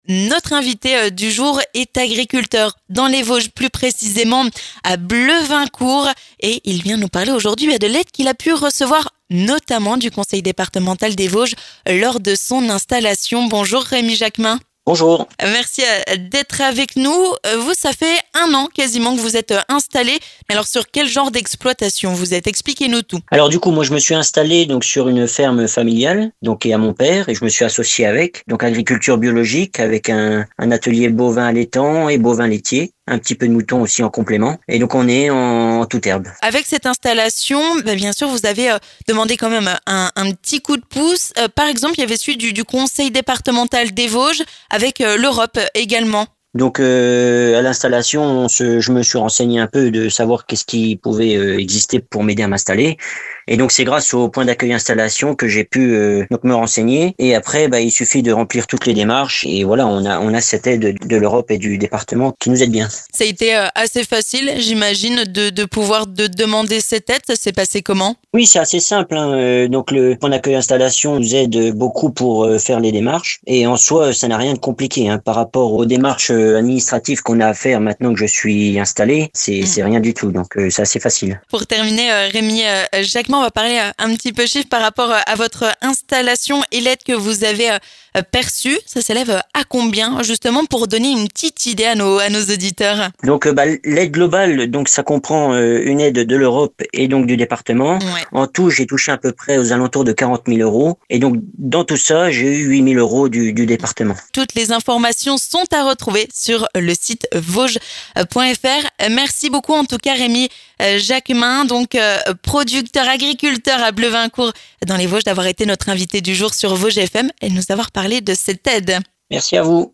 L'invité du jour
Aidé par le Conseil départemental des Vosges, un agriculteur témoigne